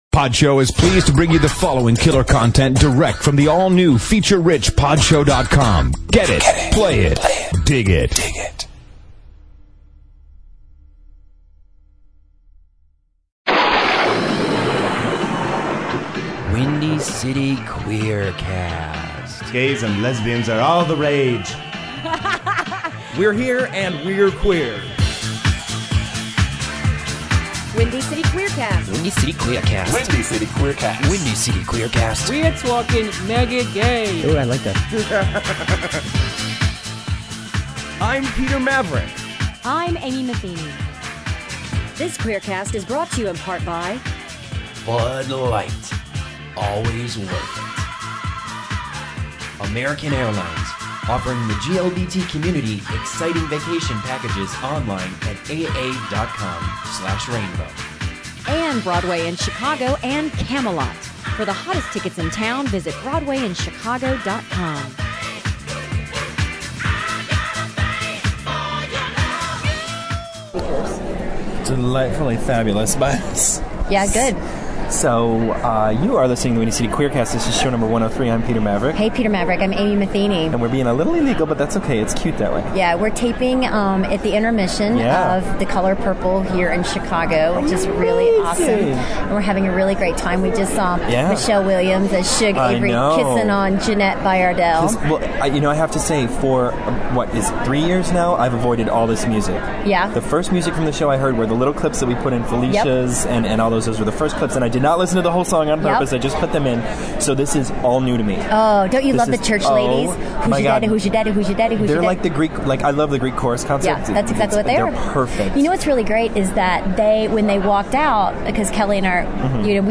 We're recording where we're not supposed to, but it was necessary and fabulous!